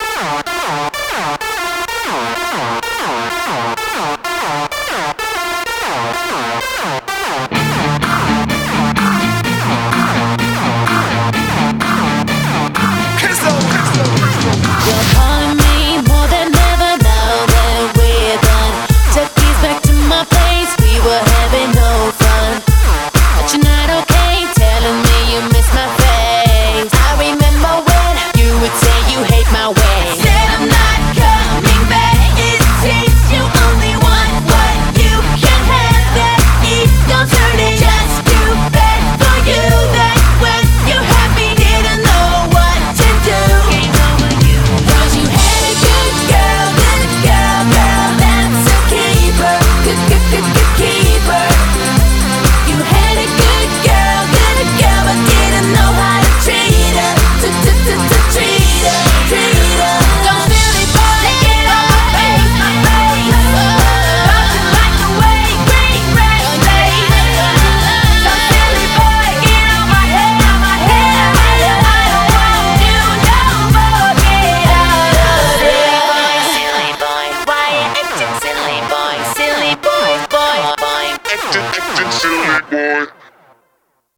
BPM127
Audio QualityMusic Cut